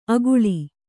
♪ aguḷi